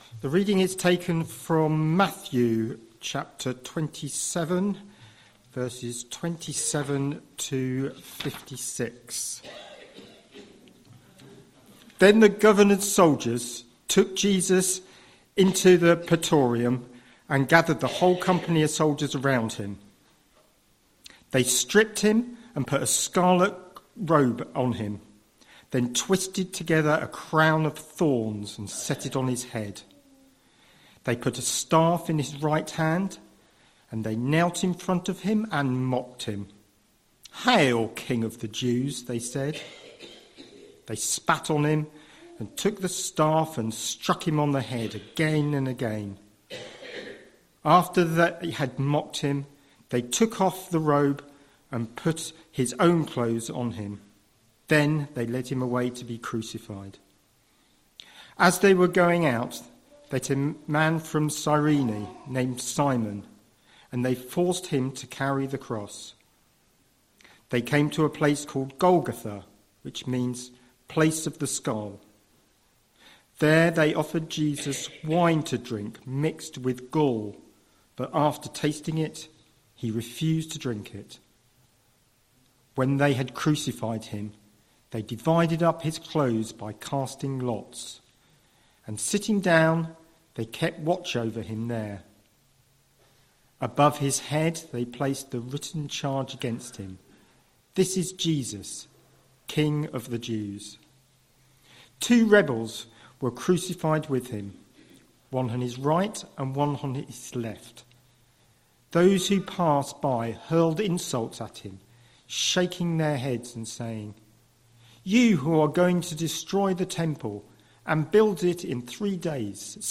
22nd March 2026 Sunday Reading and Talk - St Luke's